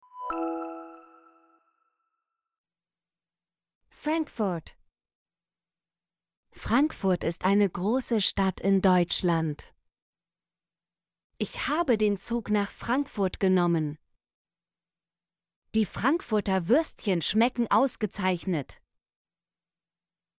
frankfurt - Example Sentences & Pronunciation, German Frequency List